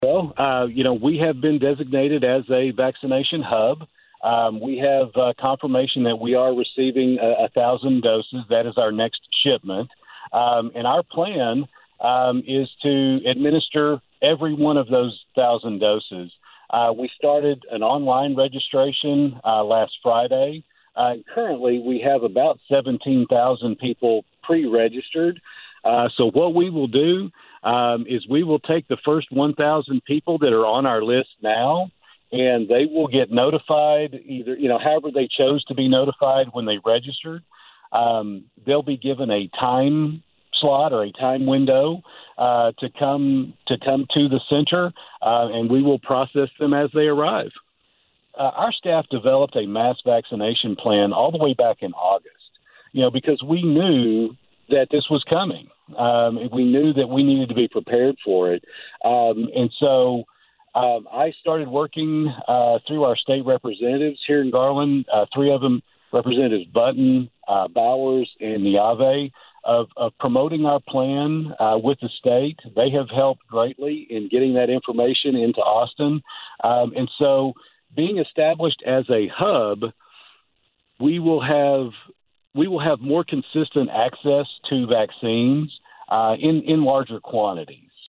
Garland Mayor Scott LeMay
GARLAND-MAYOR-web.mp3